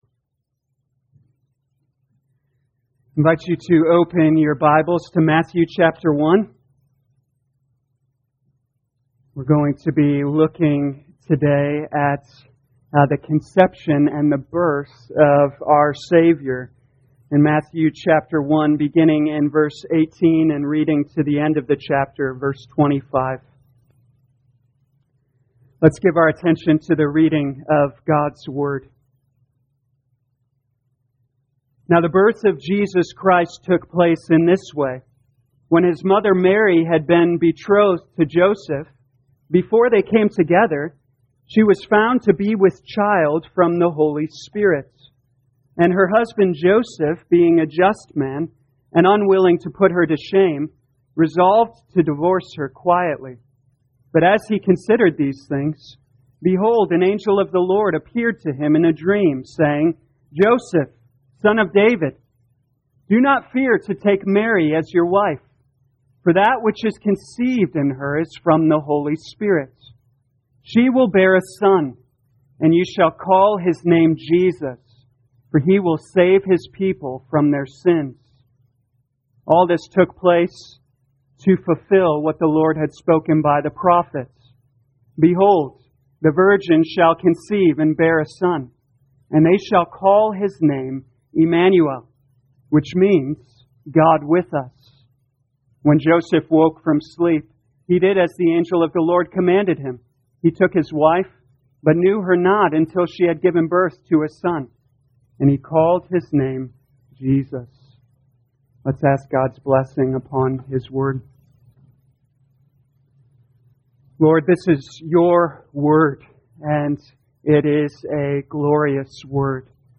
2020 Matthew Evening Service Download: Audio Notes All sermons are copyright by this church or the speaker indicated.